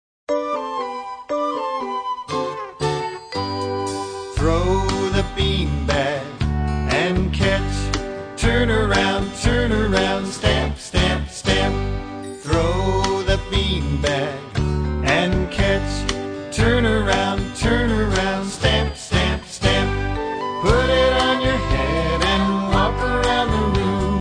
Demo MP3 Listen to the instrumental track.